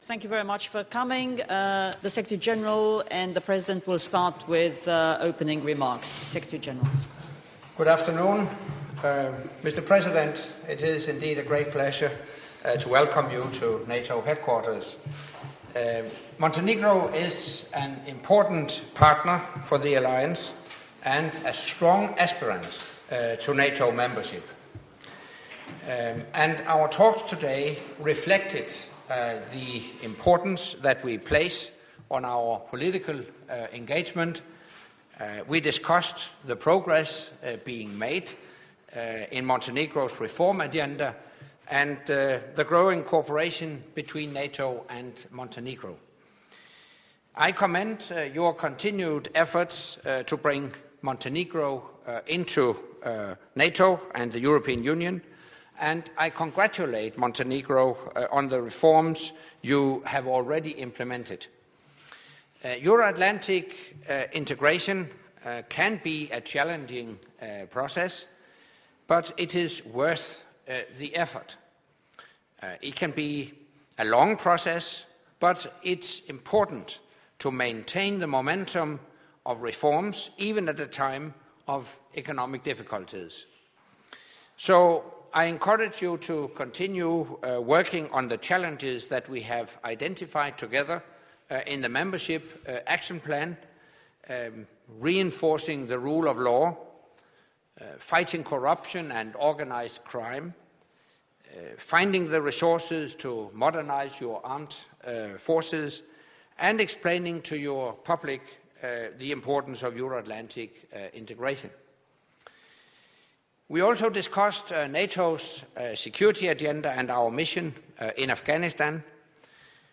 Joint press point with the NATO Secretary General and the President of Montenegro